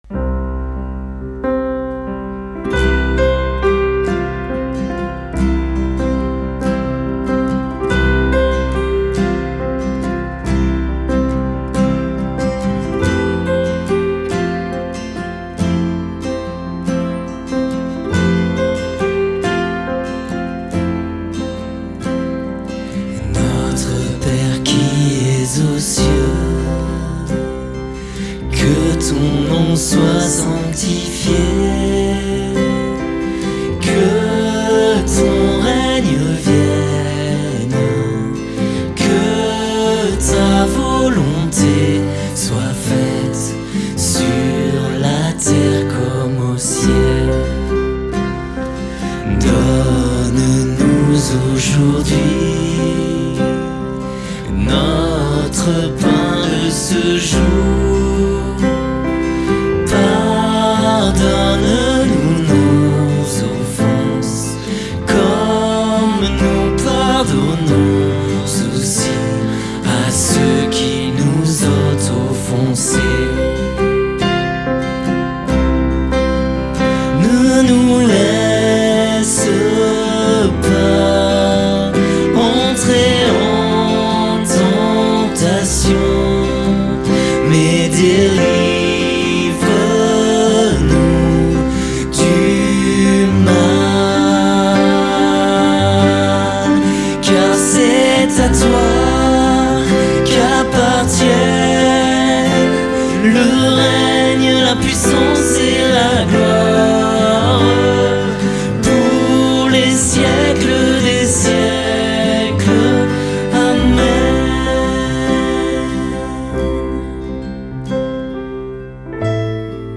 Vous trouverez en pièce jointe le magnifique Notre père chanté que nous reprendrons tous ensemble.